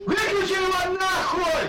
vykliuchi ego na i Meme Sound Effect
vykliuchi ego na i.mp3